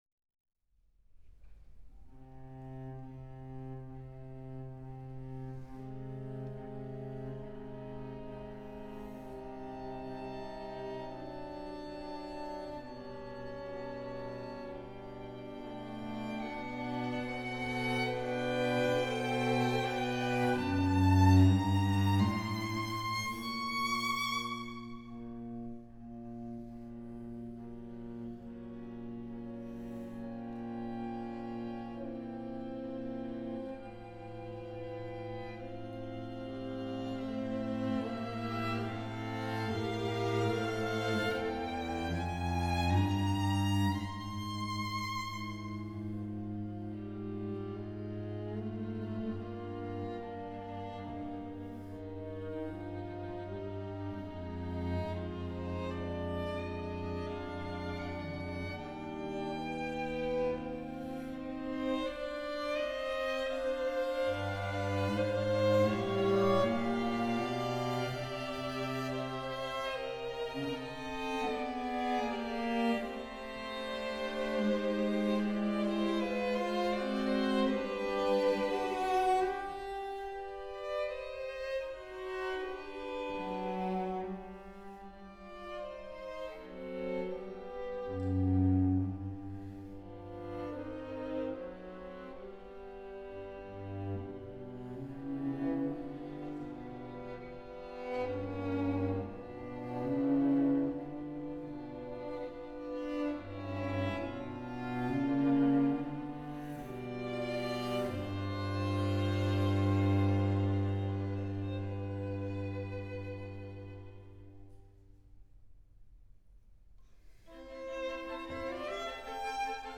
Mozart String Quartet K.465 “Dissonance” (1st movement)
Recorded live at Wigmore Hall January 2011